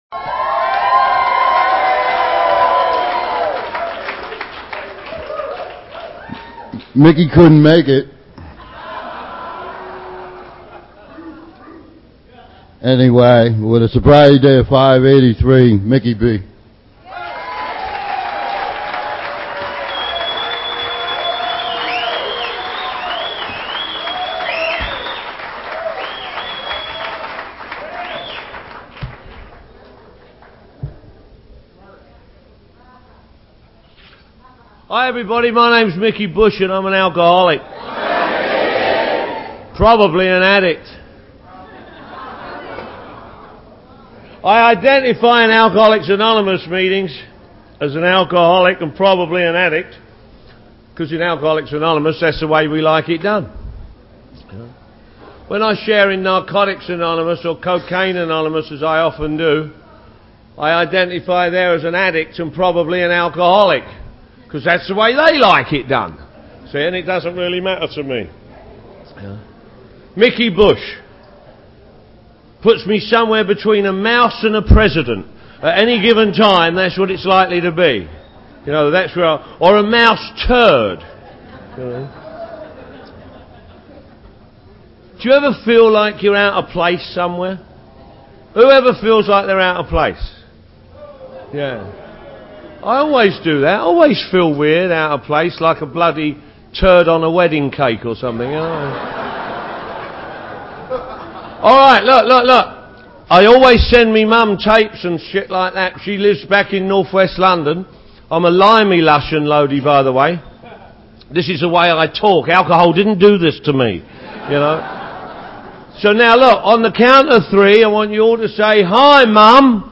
Club Sober 10th AA International Convention; San Diego, CA; 1995 | AA Speakers